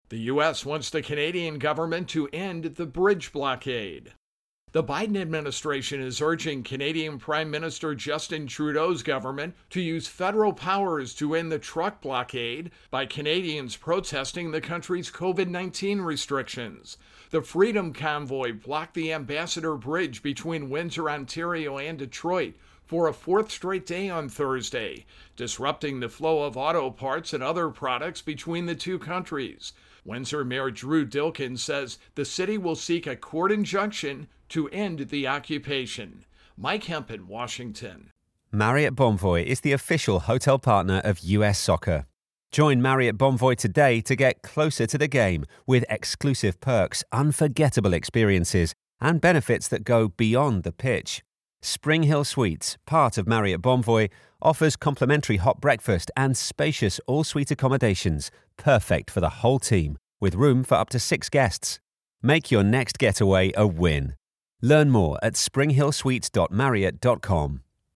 Intro and voicer for Virus Outbreak-Canada Protests.